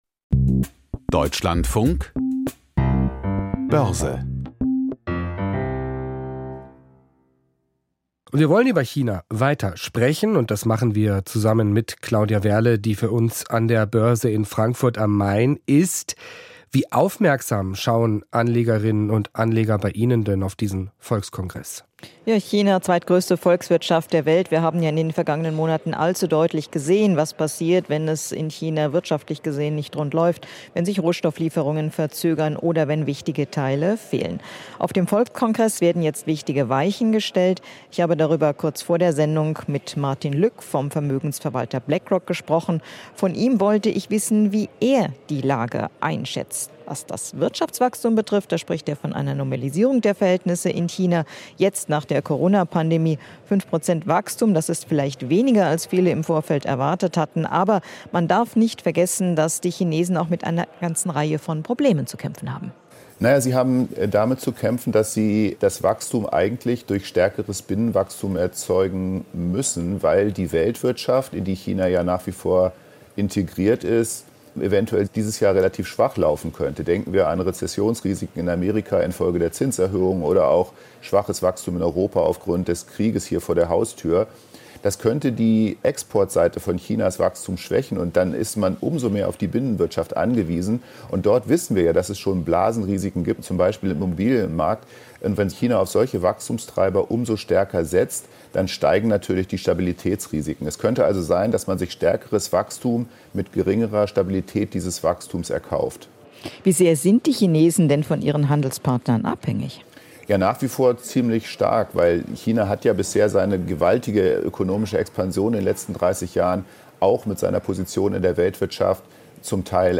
Börsengespräch aus Frankfurt